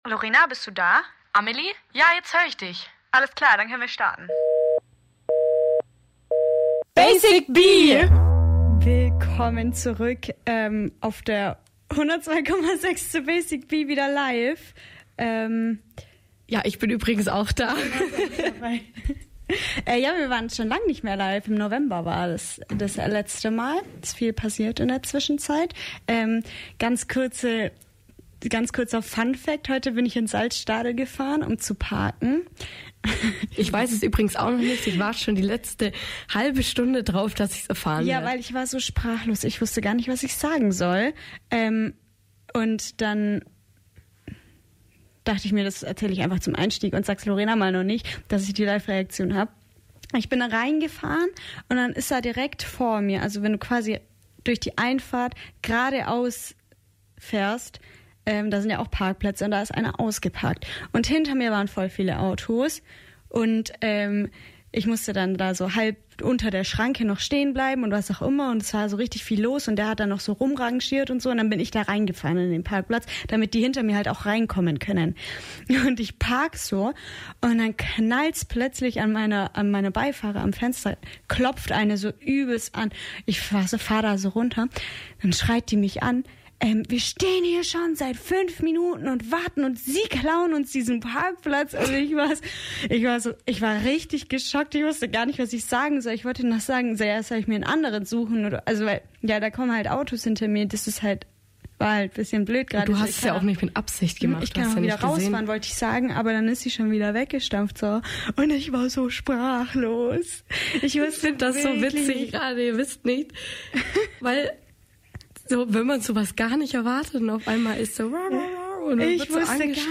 Gitarre und Gesang
Bass
Schlagzeug) um den Rock ‘n‘ Roll in der Provinz.